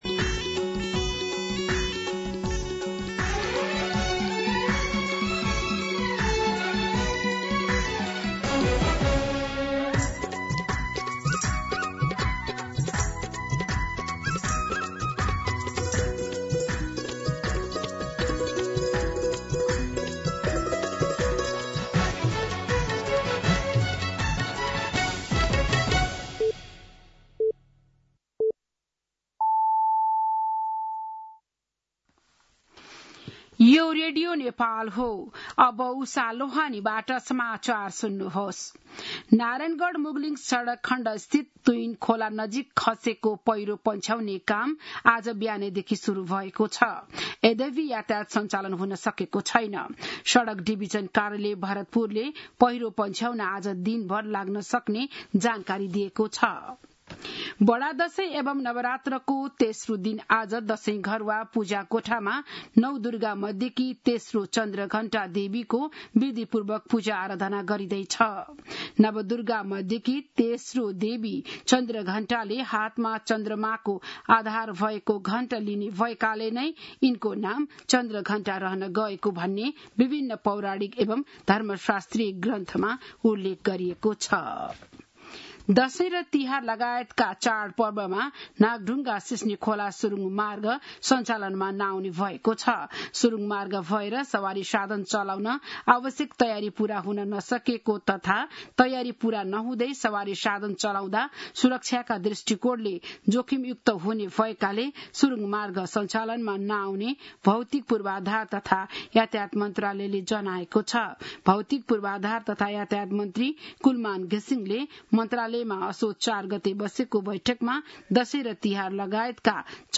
बिहान ११ बजेको नेपाली समाचार : १८ पुष , २०२६